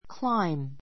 kláim ク ら イ ム （ ⦣ b は発音しない）